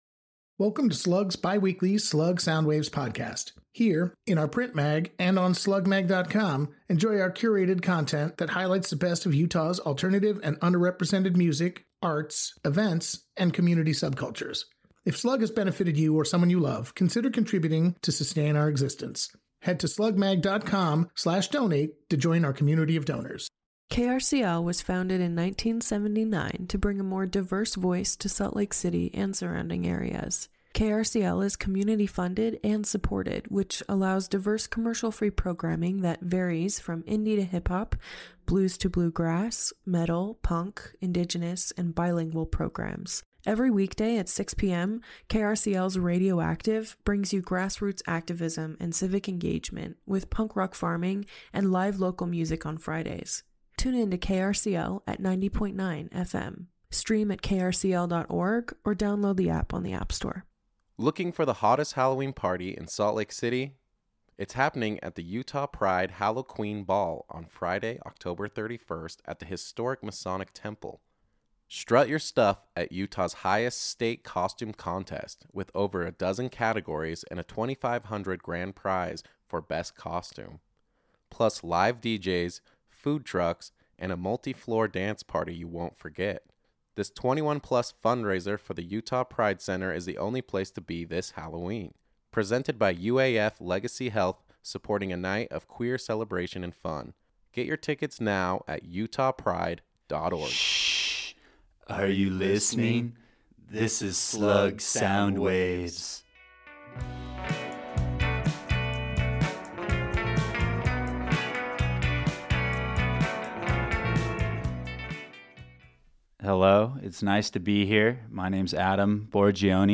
Lead vocalist
bassist
keyboardist
guitarist
drummer